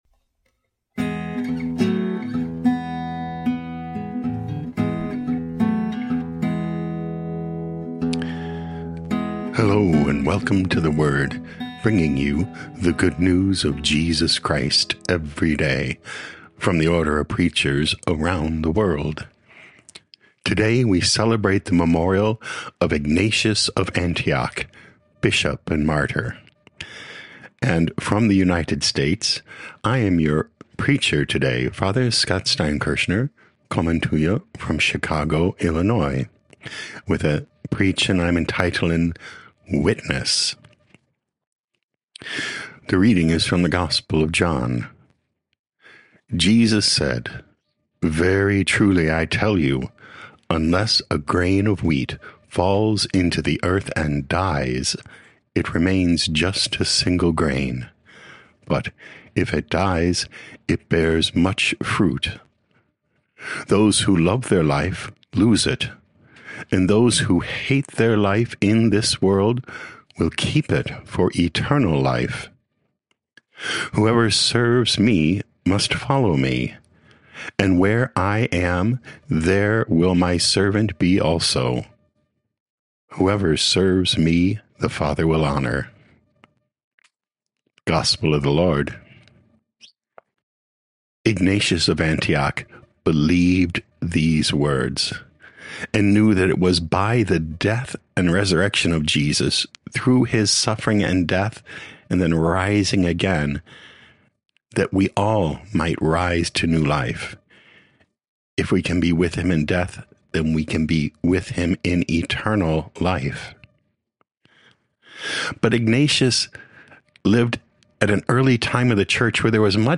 O.P. Preaching Tags